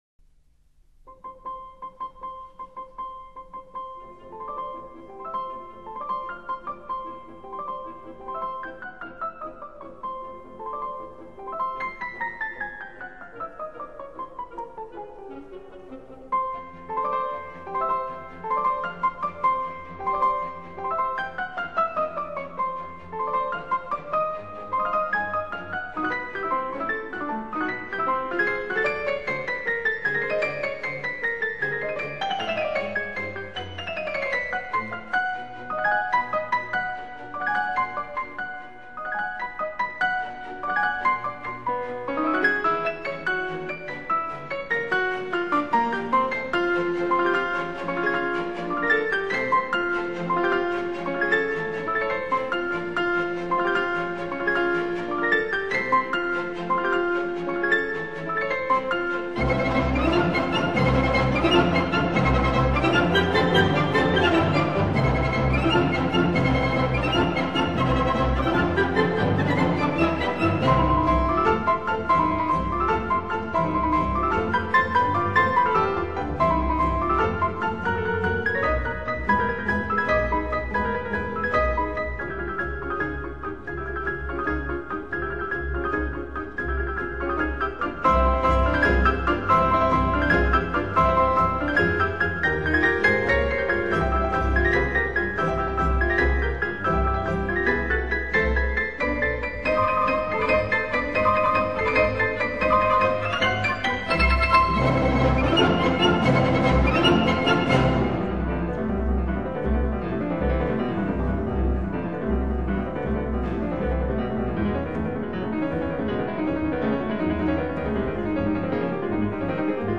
分辑：CD17  钢琴协奏曲